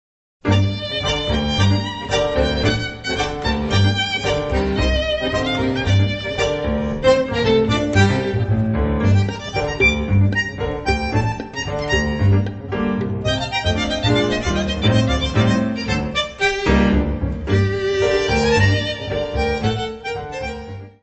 piano, congas
violino
contrabaixo
Bandoneón
: stereo; 12 cm
Music Category/Genre:  World and Traditional Music